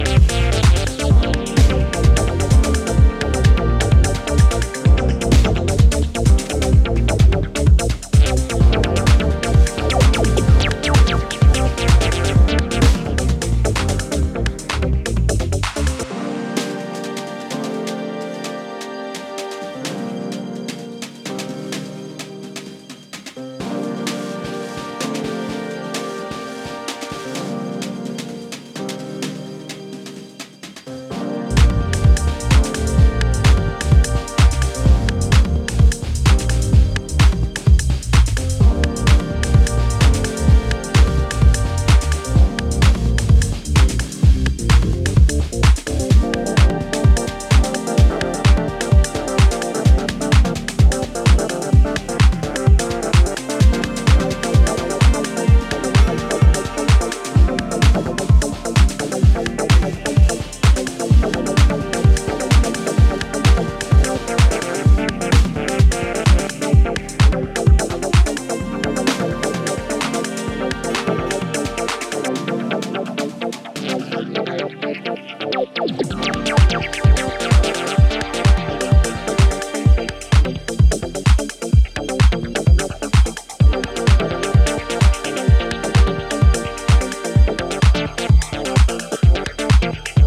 ジャジーなピアノやシンセストリングス、サイケデリックなリード等の絡みを主題に128BPMで進行するミニマル・テック